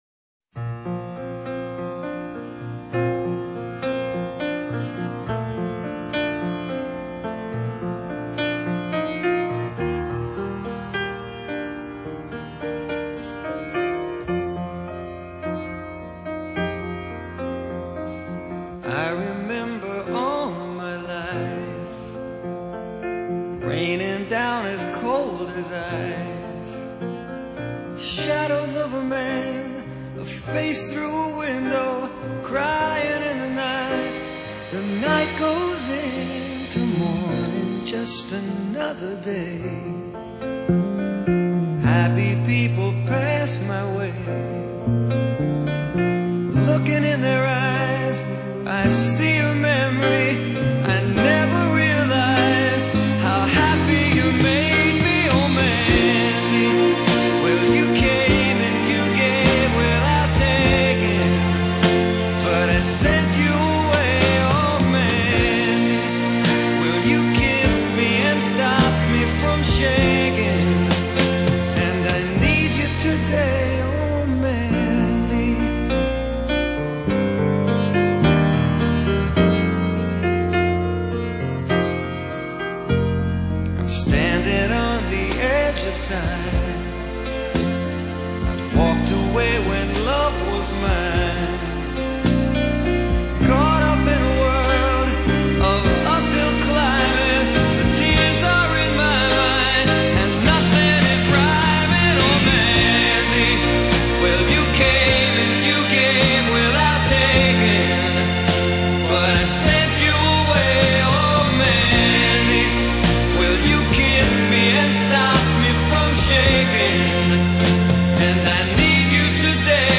Pop Ballads, Easy Listening